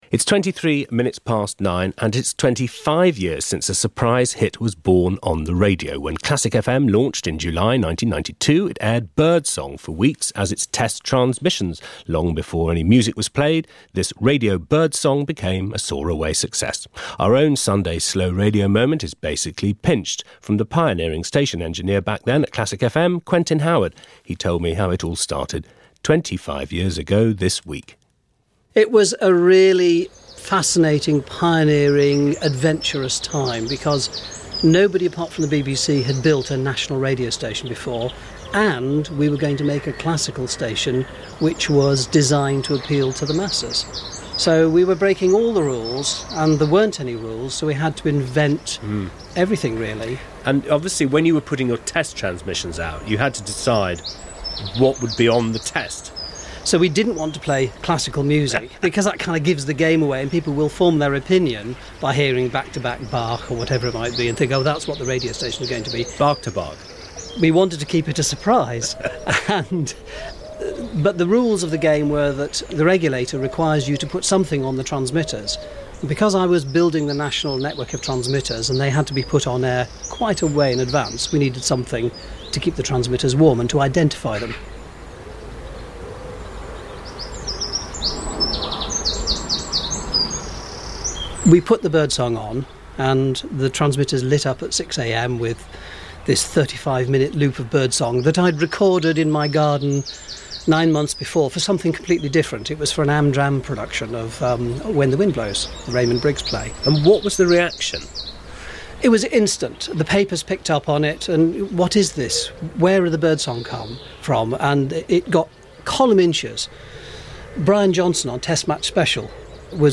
Wynford Vaughan-Thomas describes the scene inside the Westminster Abbey for the BBC Home Service and then crosses to Richard Dimbleby witnessing the processional route back to Buckingham Palace. Finally, as the crowds await the balcony appearance, the legendary Frank Gillard, who was to be BBC Local Radio's founding father, takes over.